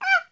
monster2 / monkey / damage_1.wav
damage_1.wav